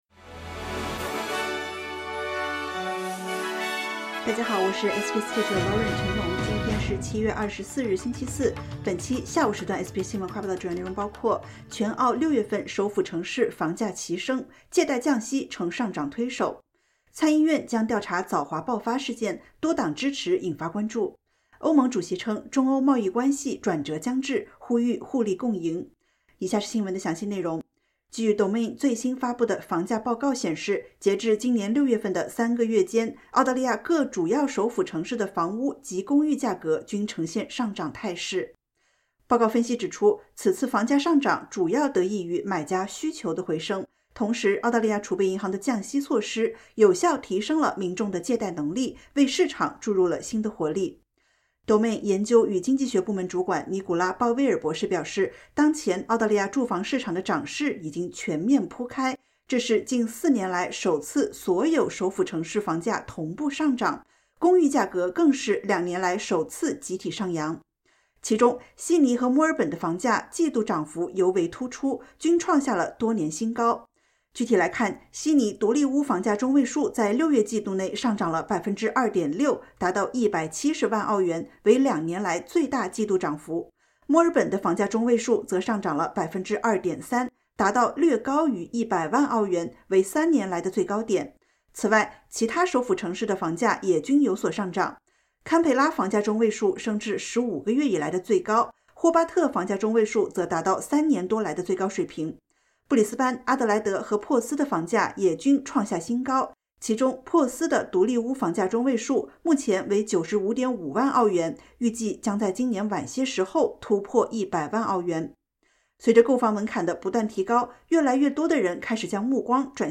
【SBS新闻快报】全澳6月首府房价齐升 借贷降息成上涨推手